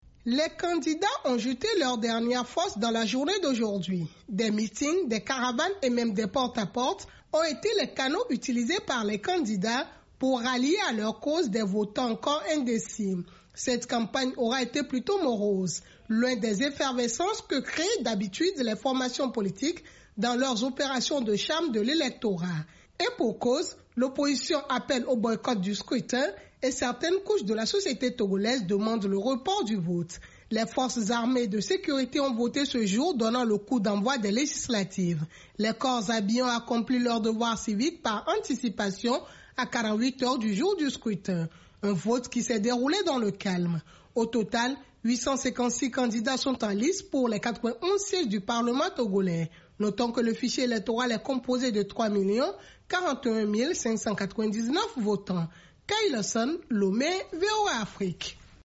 Au Togo, la campagne électorale pour les législatives du 20 décembre s’achève ce mardi à minuit. Les détails avec notre correspondantes à Lomé.